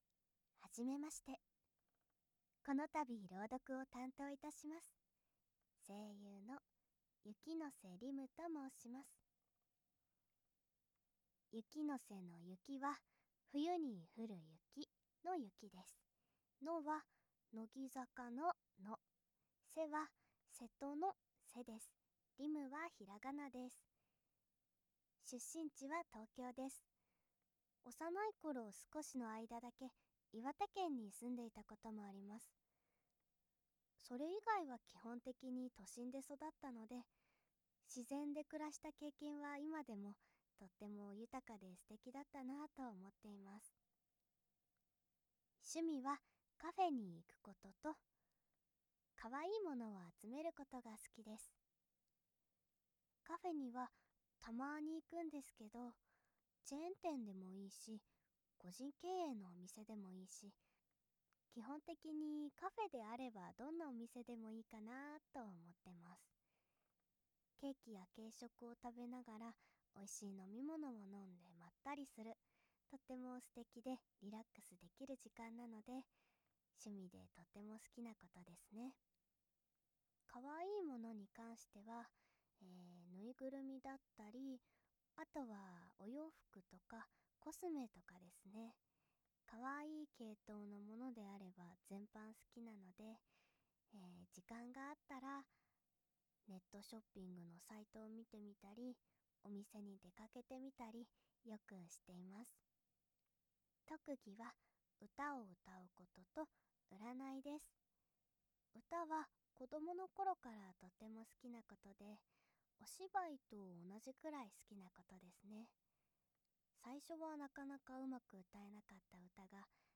✨最後まで絶対に聴けない睡眠音声✨寝落ち必至✨添い寝しながらゆるふわ系の理想の彼女があまあま「シンデレラ」を朗読してくれる催眠音声 - ASMR Mirror